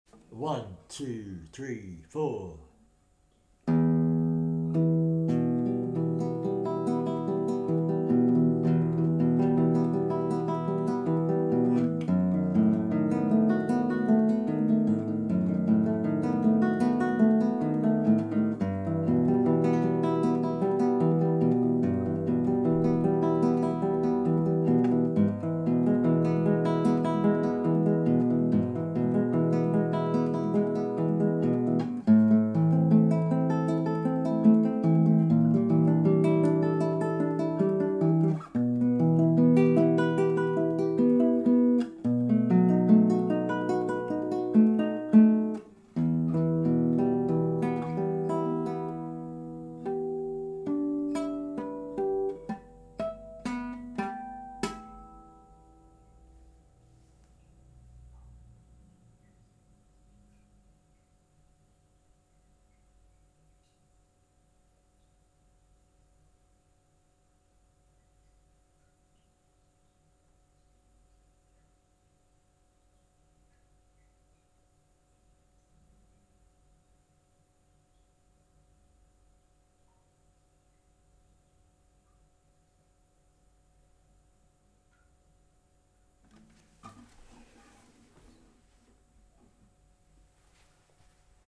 Mics into a MOTU M4 and 1 mtr away. Not sure of the setup but I think he was going to try ORTF, 110dgr angle.
There's definitely noise there, but I'd guess it's environmental.